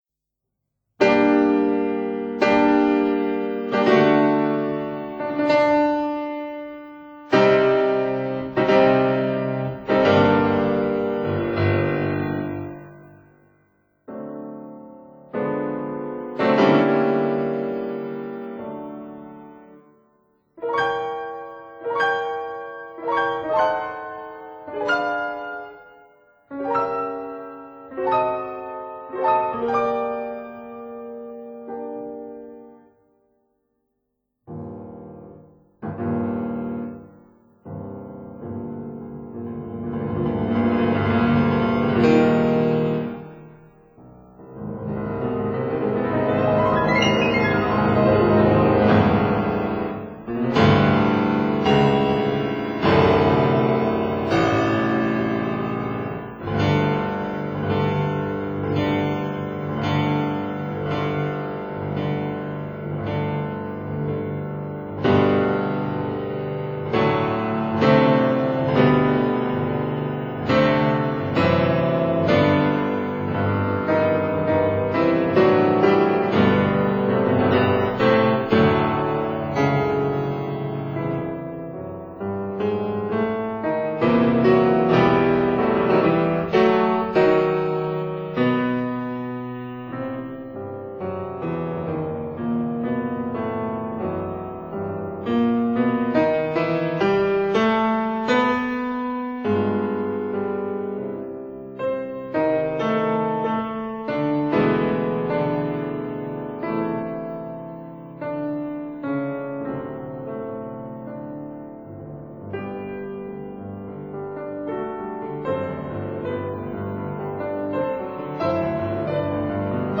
L'art du chant appliqué au piano